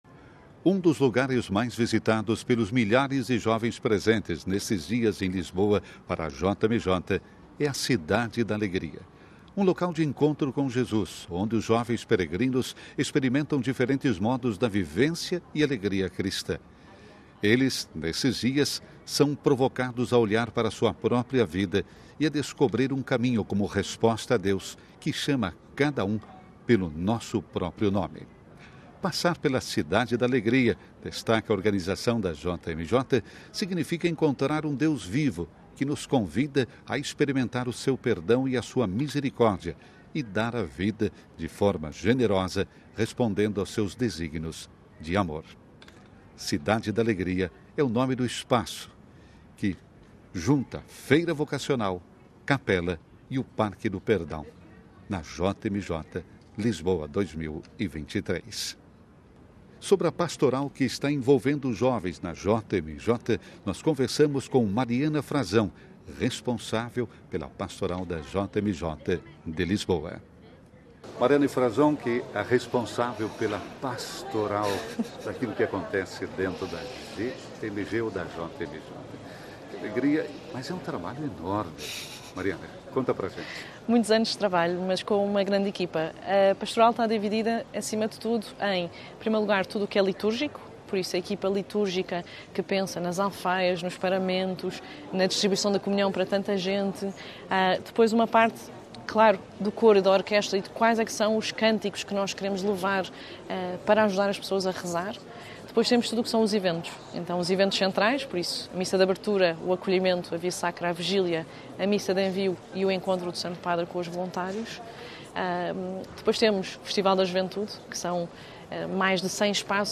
Lisboa – Vatican News